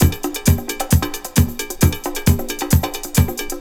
Index of /90_sSampleCDs/E-MU Formula 4000 Series Vol. 2 – Techno Trance/Default Folder/Tribal Loops X
TRIBAL LO03L.wav